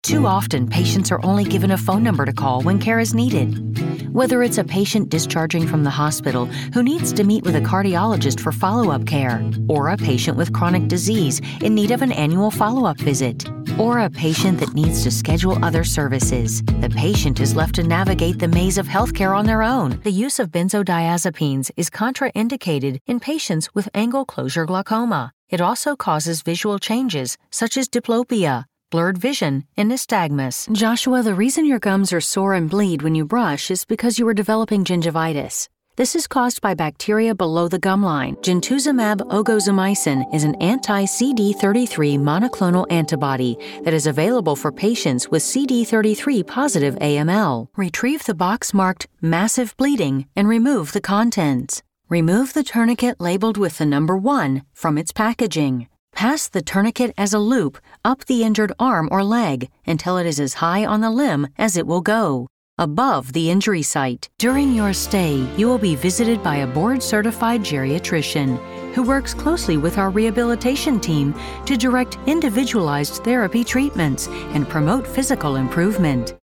Narração Médica
Cabine de isolamento WhisperRoom, microfone Roswell Pro Audio RA-VO, interface Sound Devices USBPre2, computadores Mac, Source Connect